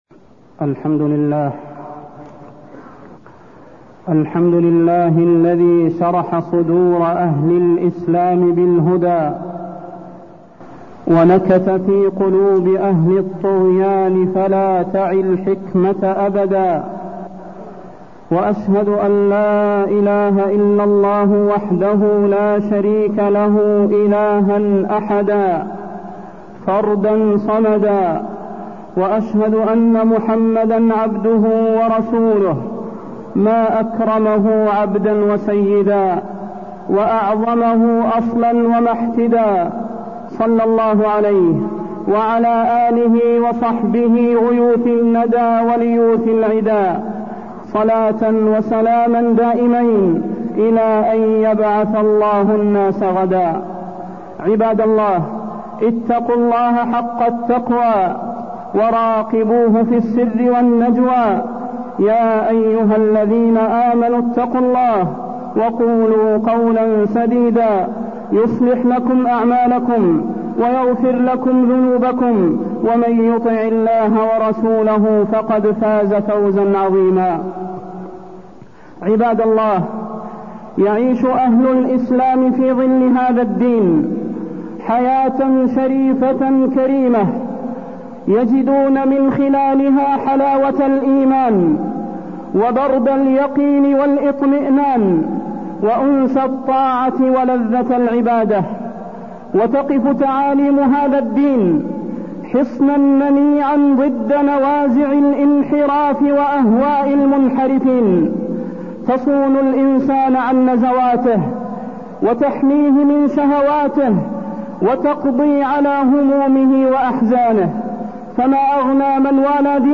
تاريخ النشر ٧ ربيع الأول ١٤٢١ هـ المكان: المسجد النبوي الشيخ: فضيلة الشيخ د. صلاح بن محمد البدير فضيلة الشيخ د. صلاح بن محمد البدير الأغاني